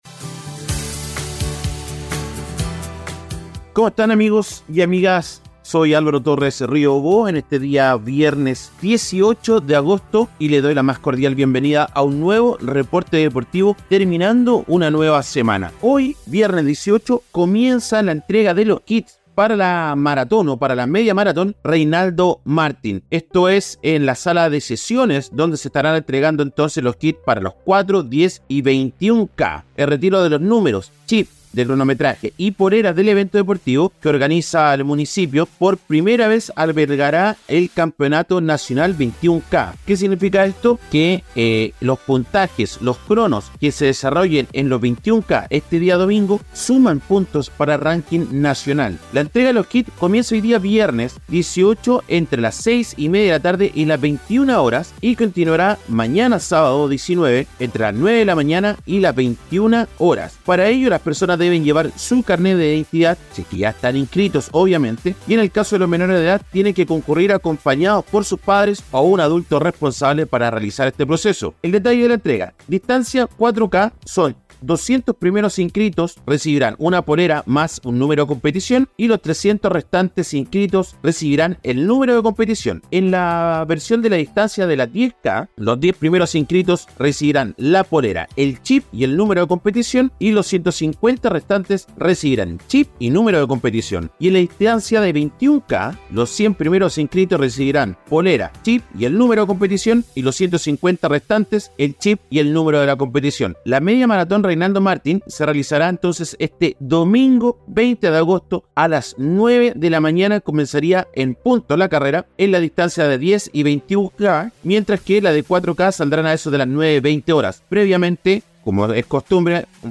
En este episodio, les traemos un breve reporte con las "deportivas" más destacadas de las últimas 24 horas.